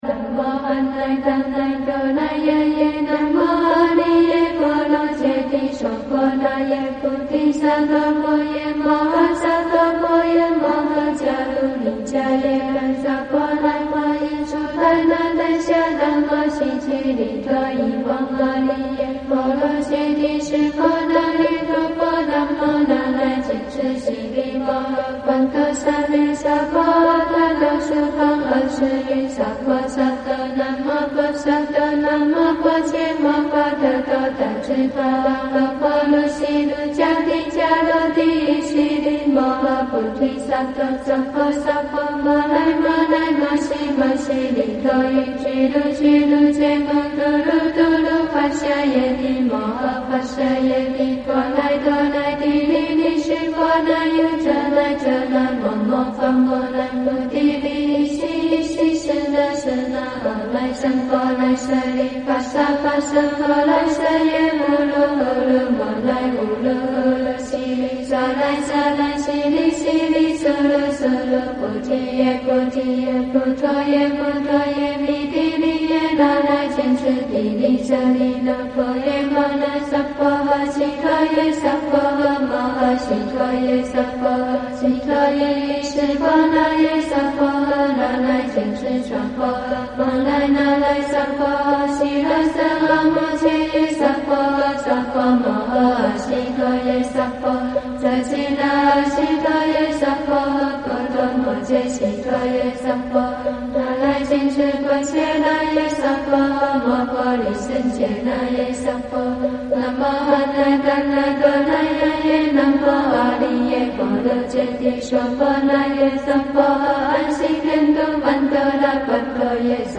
Miraculous chant given to the world by